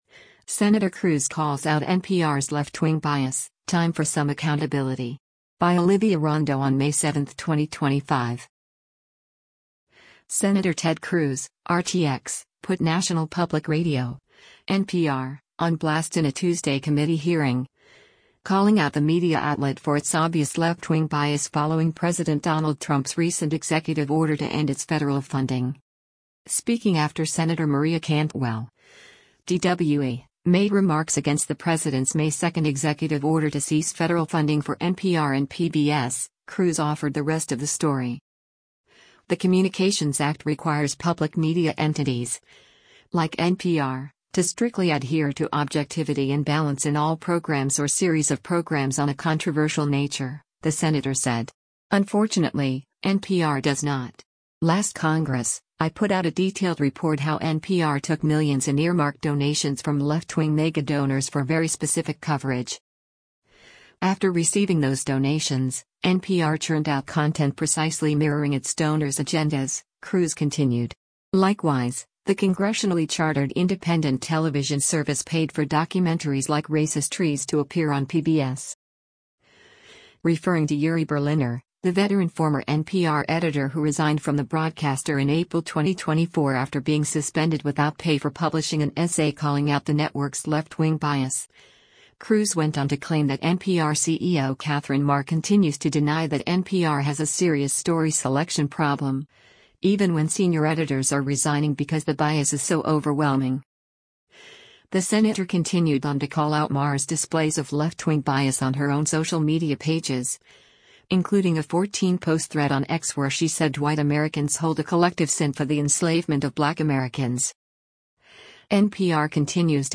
Sen. Ted Cruz (R-TX) put National Public Radio (NPR) on blast in a Tuesday committee hearing, calling out the media outlet for its obvious left-wing bias following President Donald Trump’s recent executive order to end its federal funding.